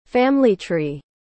¿Cómo se pronuncia árbol genealógico en inglés (family tree)?
• Family: Divide la palabra en dos partes – “FAM” (como en «famoso») y “uh-lee”.
• Tree: Se pronuncia “trii”, con una “i” larga.